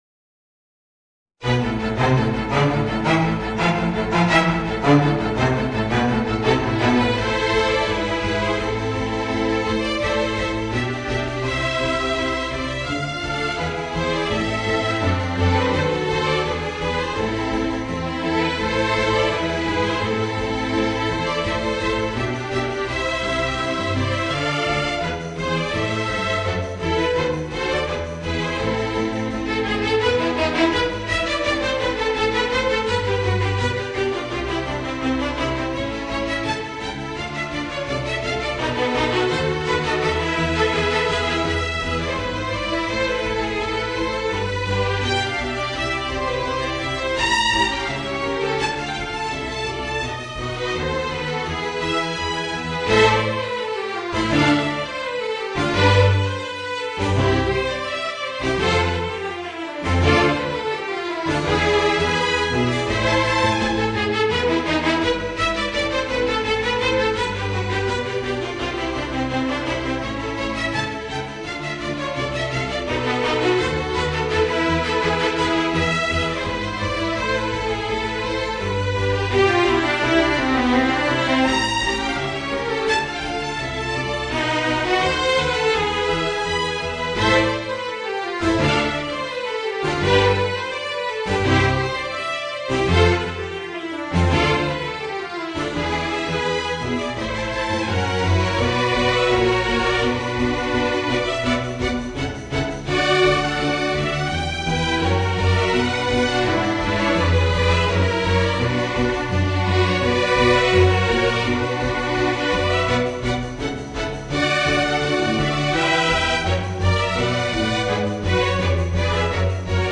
Voicing: String Quartet